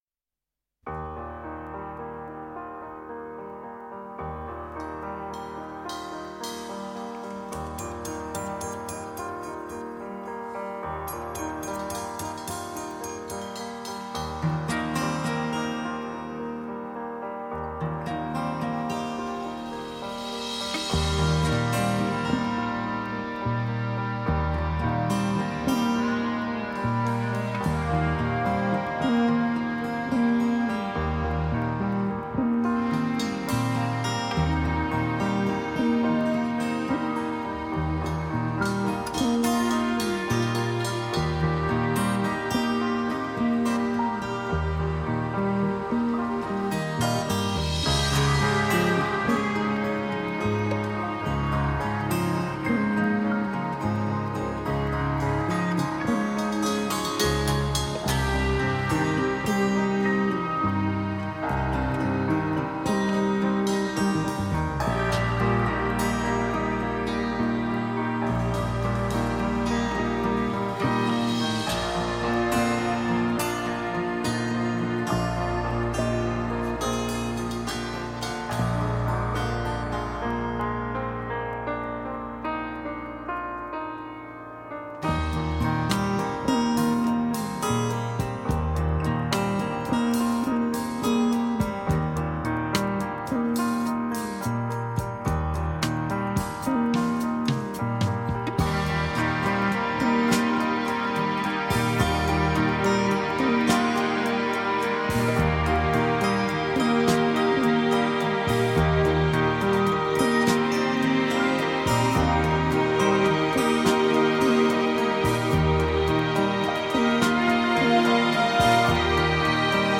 radiomarelamaddalena / STRUMENTALE / PIANO /